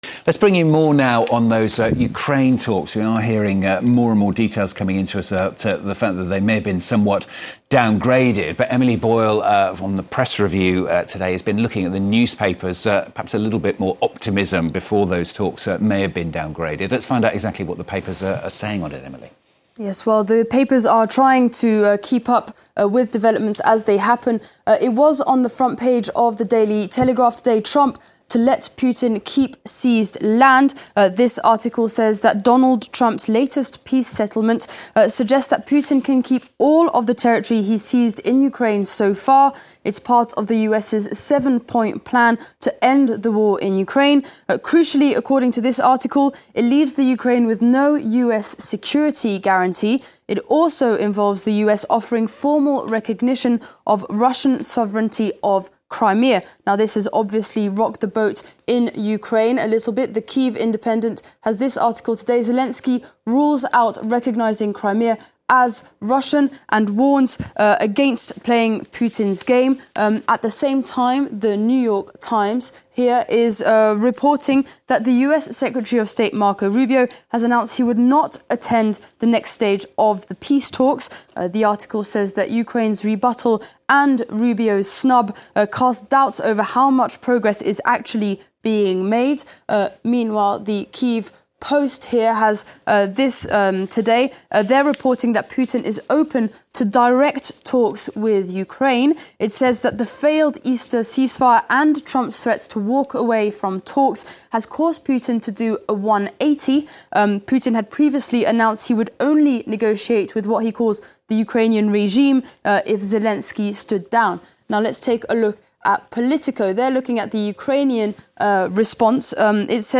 PRESS REVIEW – Wednesday, 23 April. A ceasefire between Ukraine and Russia appears to be making progress, albeit extremely slowly.